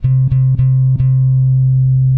描述：在果味循环中制作的较长的柔和的真实的低音声音
标签： 110 bpm Acoustic Loops Bass Loops 751.85 KB wav Key : F